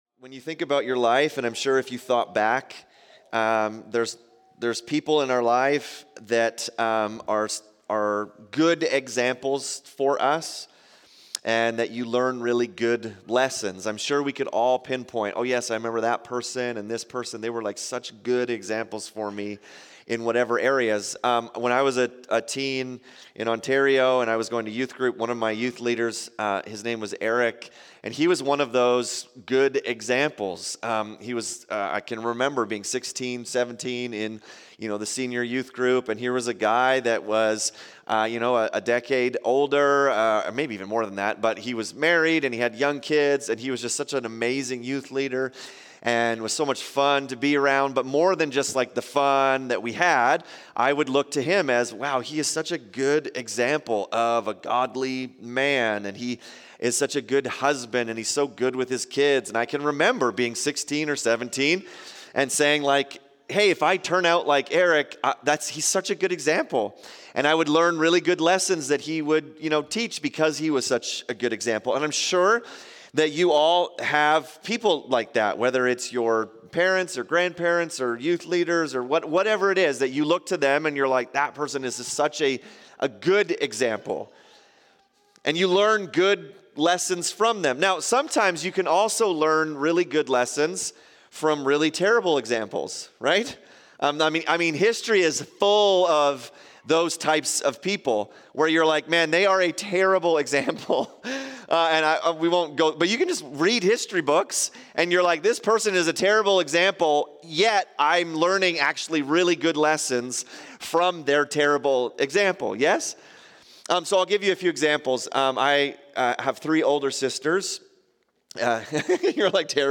Sermons | North Peace MB Church